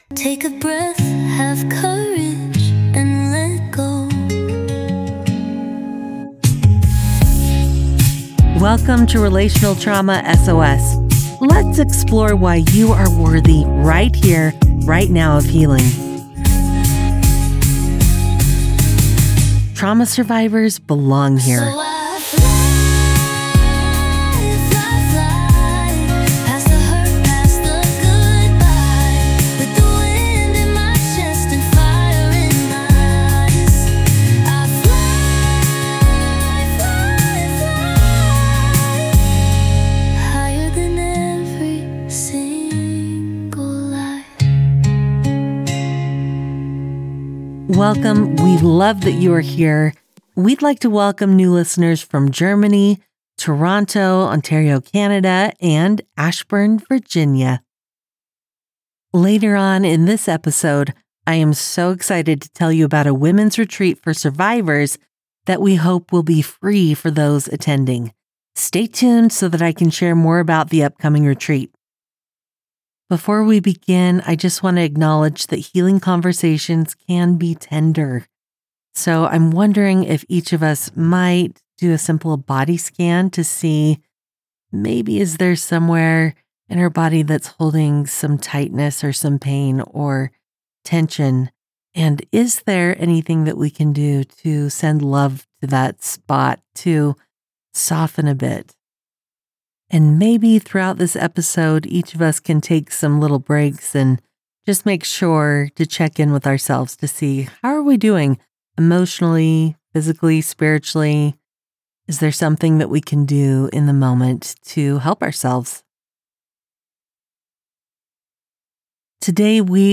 Join us for a soft, soul-grounding conversation on: What makes healing feel so hard to claim How